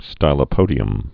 (stīlə-pōdē-əm)